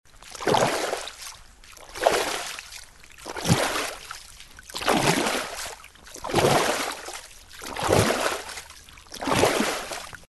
На этой странице собраны натуральные звуки рек и ручьев в высоком качестве.
Шум плавания человека в реке